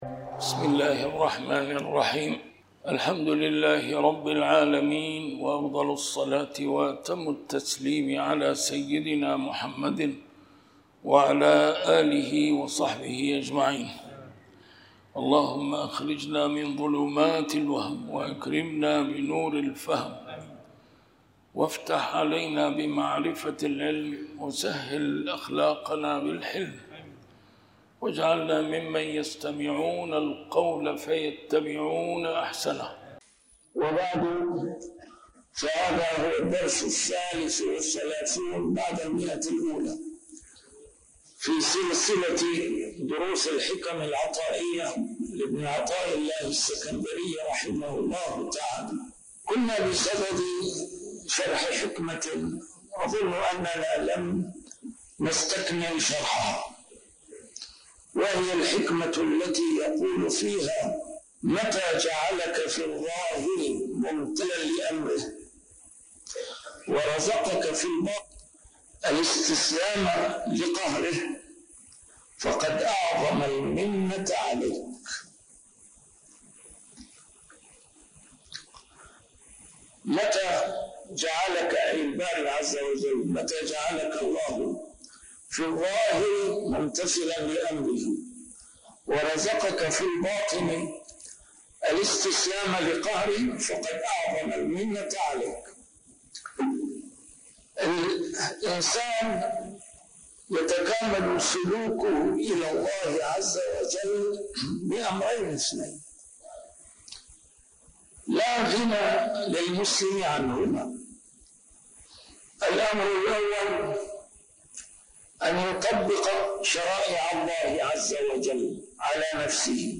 نسيم الشام › A MARTYR SCHOLAR: IMAM MUHAMMAD SAEED RAMADAN AL-BOUTI - الدروس العلمية - شرح الحكم العطائية - الدرس رقم 133 شرح الحكمة 110+111